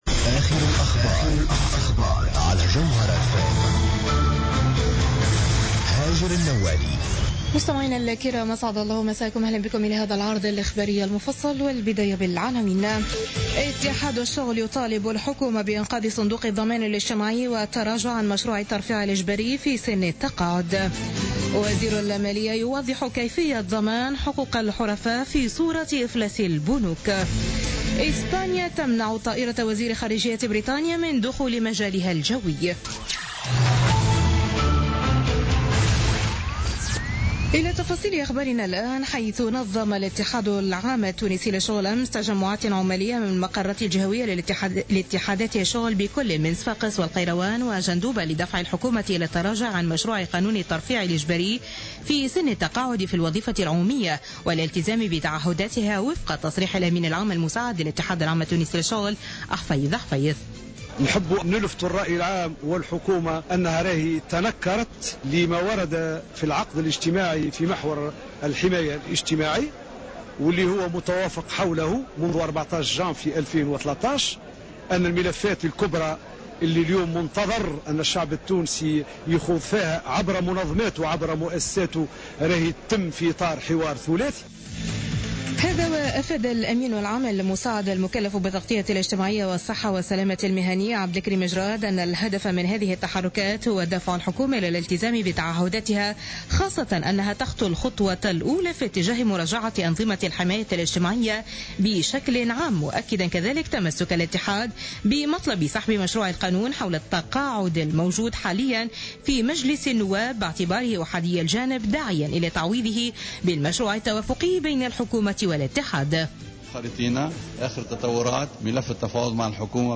Journal Info 00h du lundi 16 mai 2016